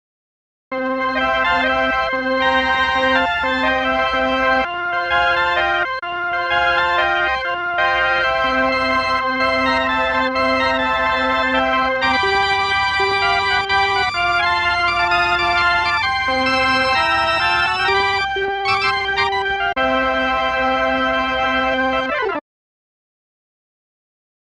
05-Orchestron Organ LP
05-Orchestron-Organ-LP.mp3